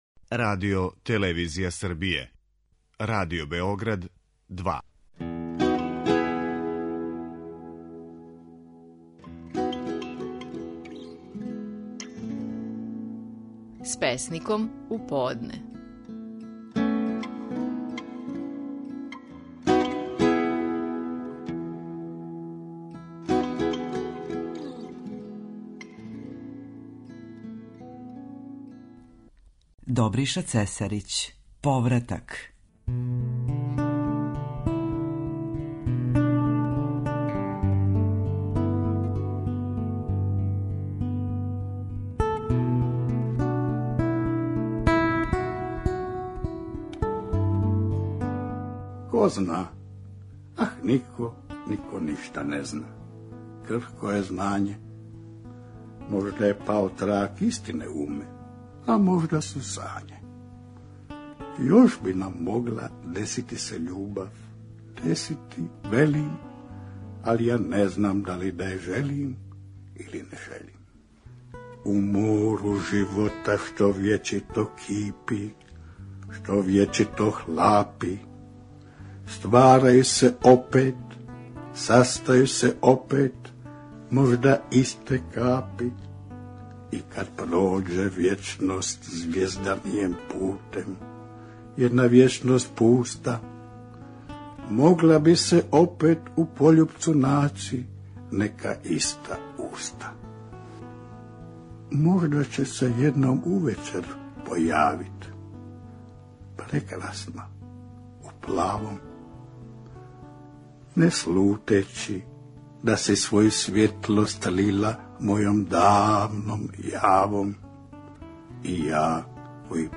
Наши најпознатији песници говоре своје стихове
„Повратак” је назив песме коју говори Добриша Цесарић.